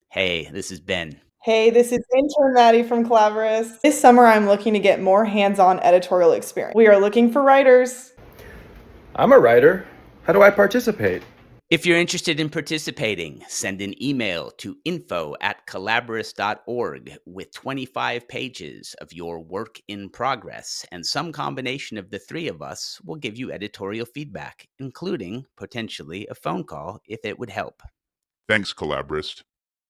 Free 25 Page Editing Offer (this is a public service announcement)